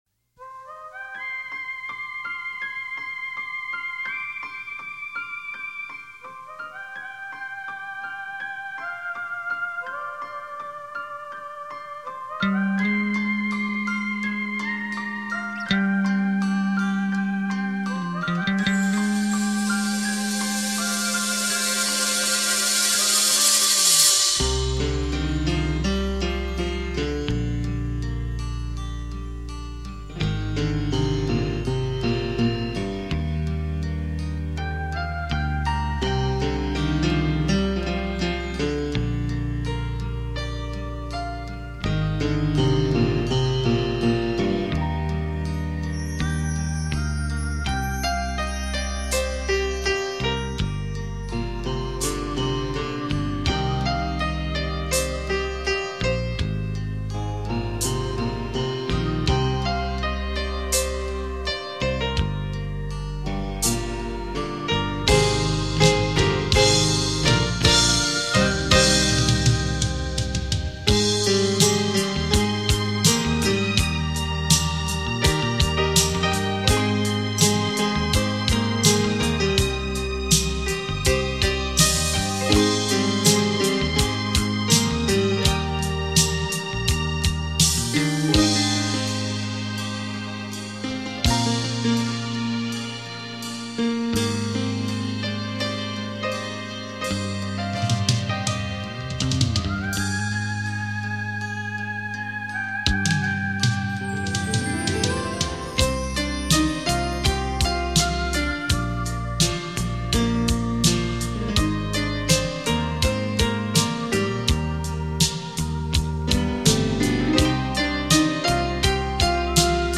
经典演奏曲
双钢琴的震撼，与您体验琴乐声交织而成的文化艺术飨宴。